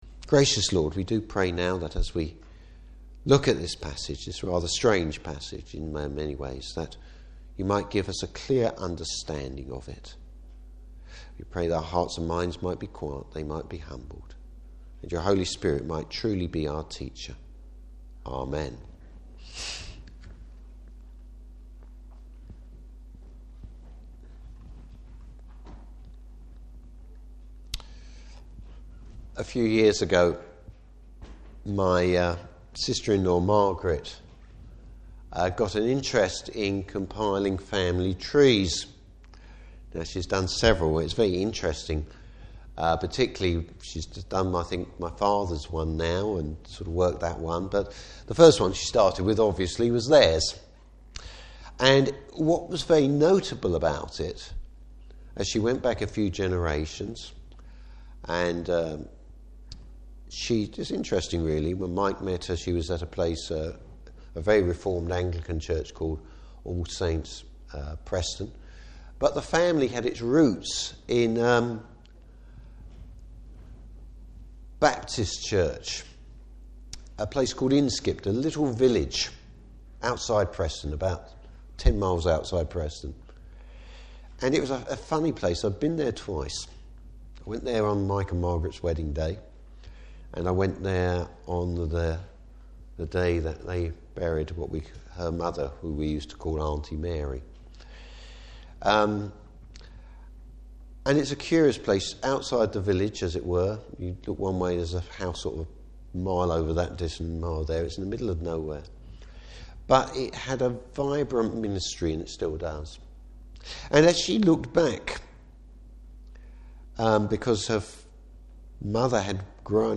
Service Type: Morning Service How wisdom is personified, both in the godhead and in Jesus Christ.